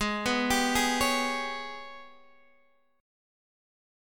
AbmM11 chord